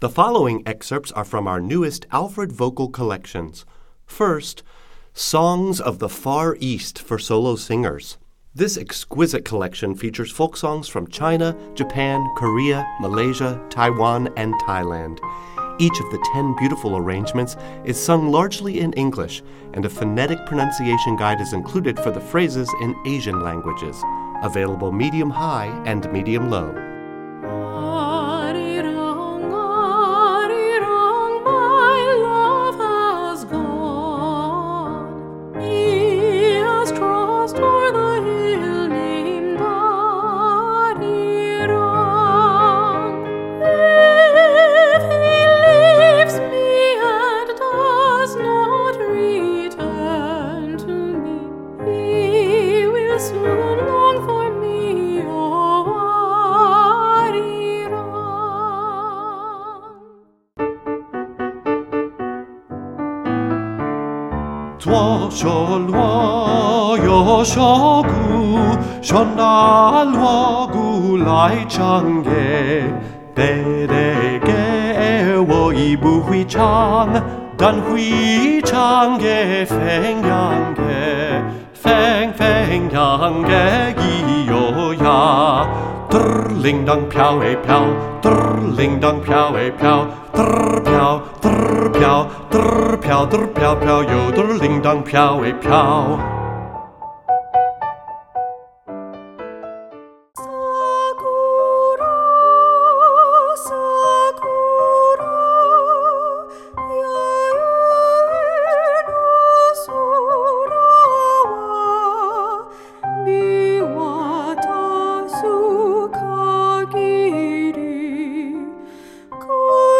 Voicing: Medium-Low Voice